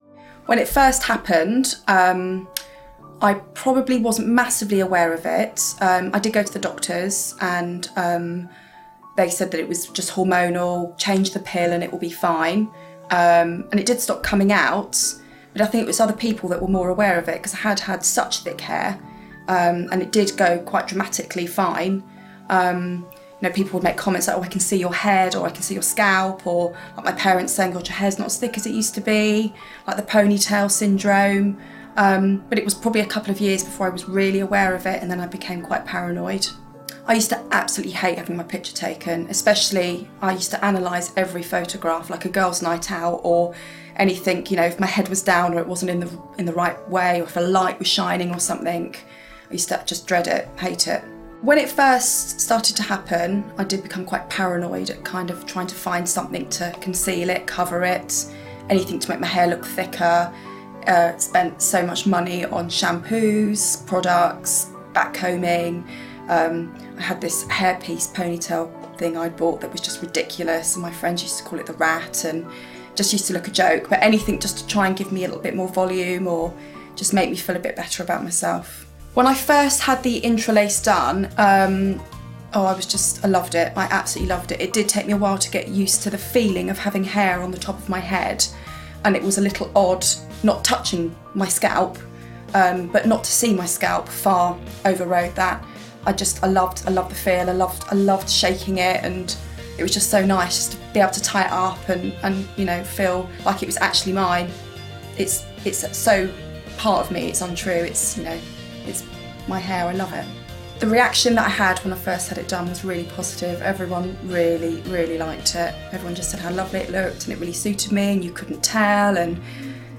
Transcription of interview - Client B